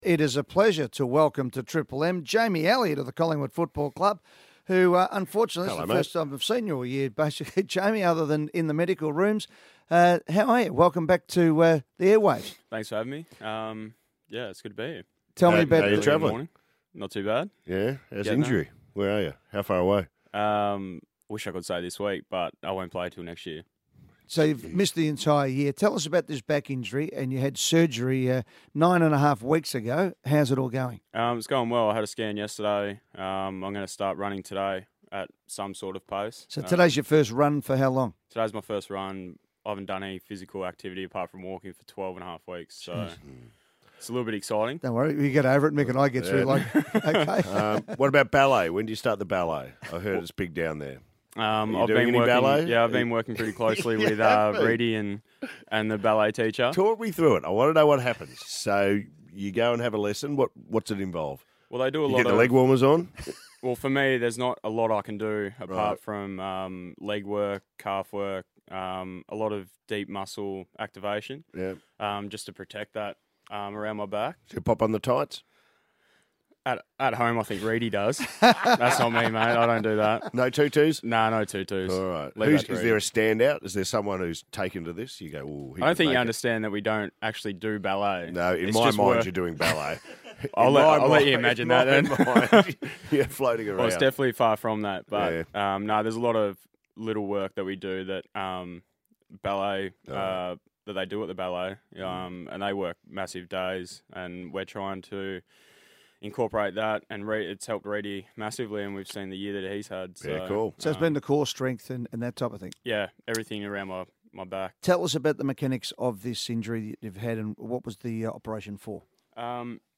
Listen to Jamie Elliott as he joined the Triple M Hot Breakfast on Friday morning.